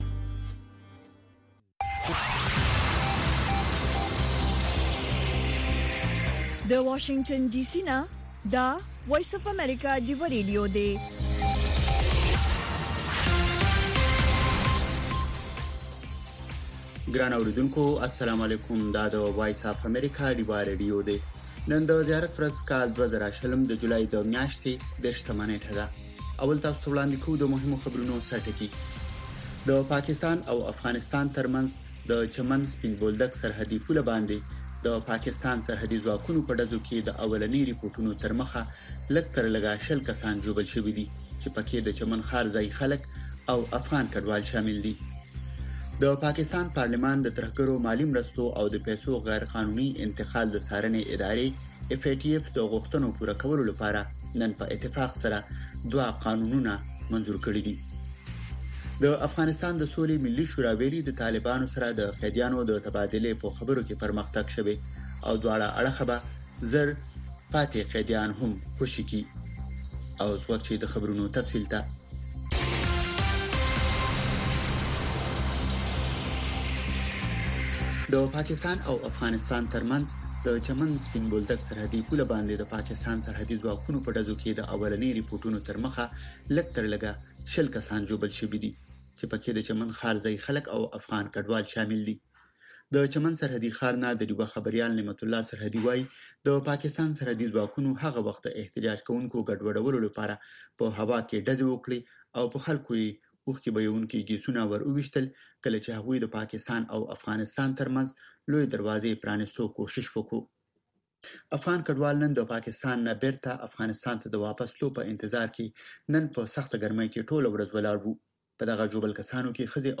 خبرونه
د وی او اې ډيوه راډيو ماښامنۍ خبرونه چالان کړئ اؤ د ورځې د مهمو تازه خبرونو سرليکونه واورئ.